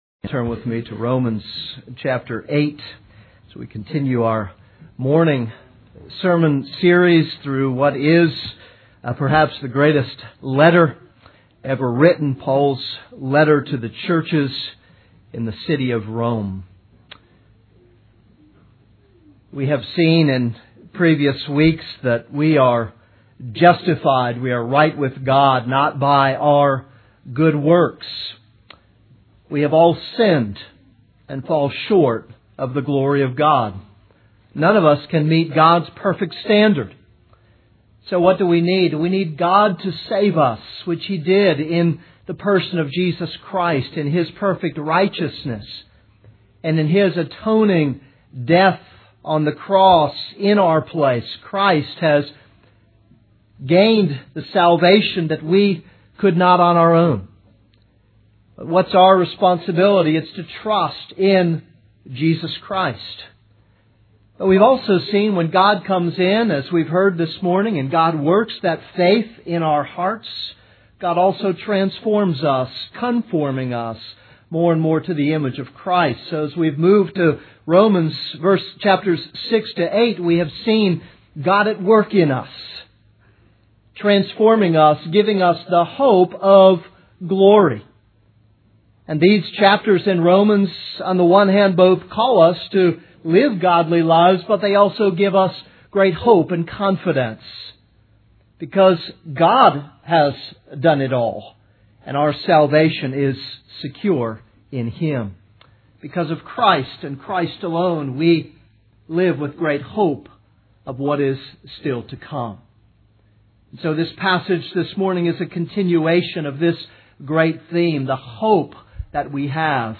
This is a sermon on Romans 8:28-30.